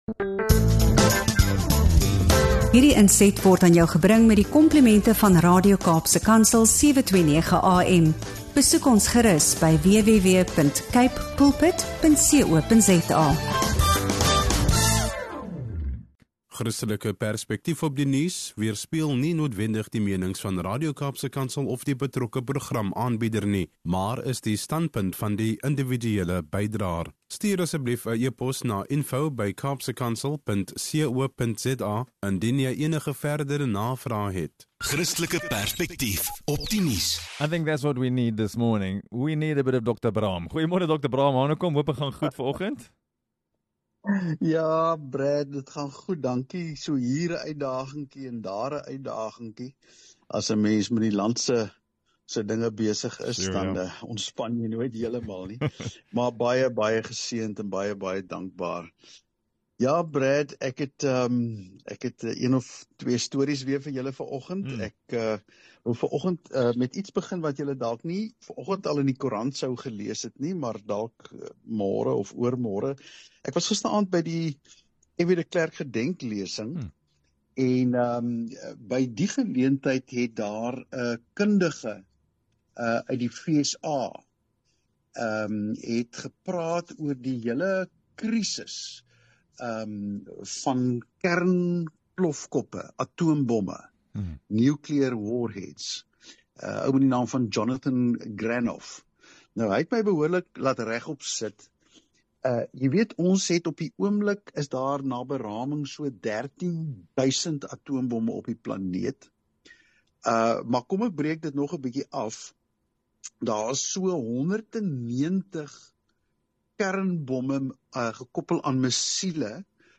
Staan die wêreld op die rand van 'n kernramp? En hoe oorleef ons die vergiftigde verdeeldheid in ons eie land? In hierdie kragtige en insiggewende gesprek